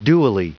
Prononciation du mot dually en anglais (fichier audio)
Prononciation du mot : dually